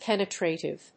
• / pénətrèɪṭɪv(米国英語)